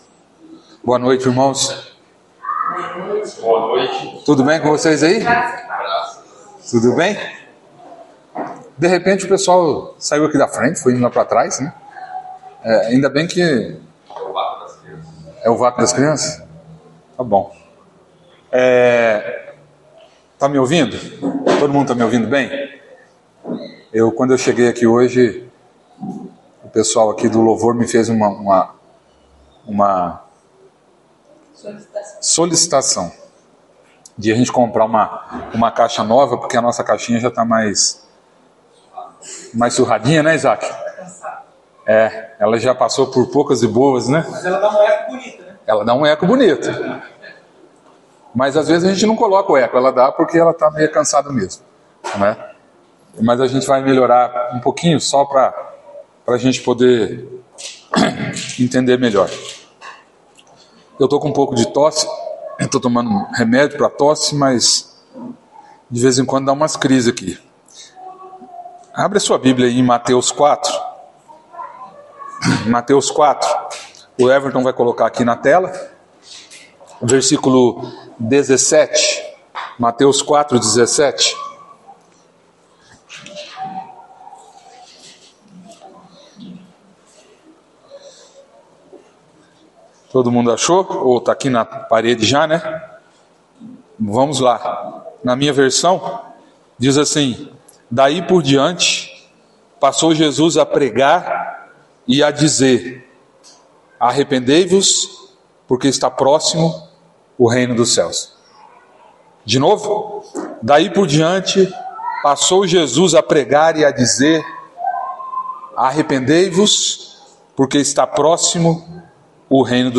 Palavras ministradas